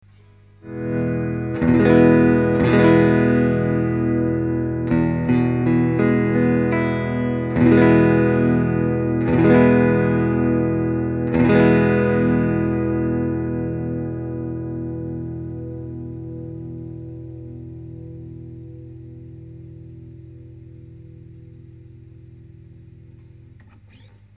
あれにもっと倍音かけた感じだば。
音も太いだば。
■アンプ：Fender　Pro-Junior（15W)
■マイク：Seide　PC-VT3000/SHURE　SM57-LCE